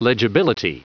Prononciation du mot legibility en anglais (fichier audio)
Prononciation du mot : legibility